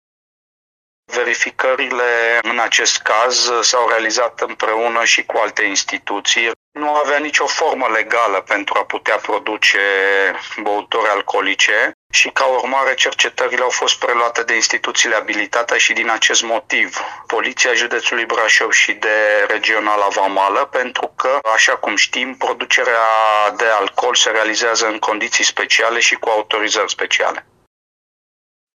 Revine Sorin Susanu: